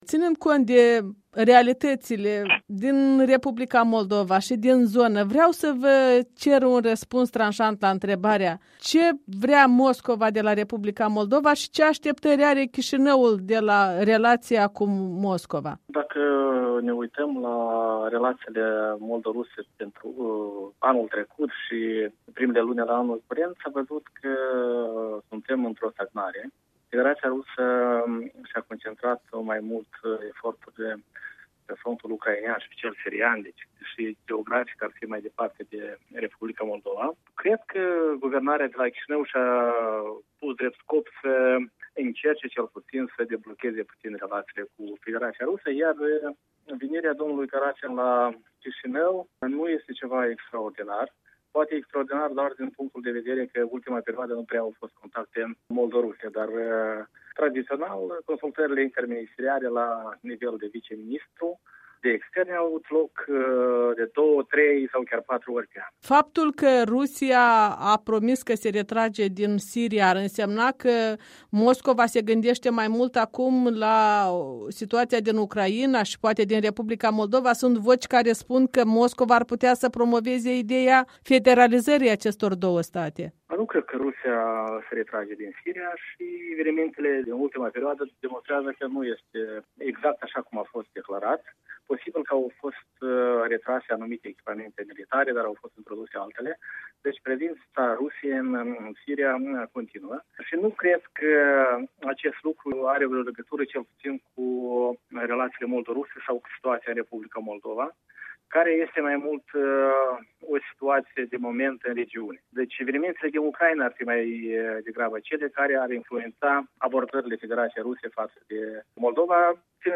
Interviu cu fostul ministru adjunct de externe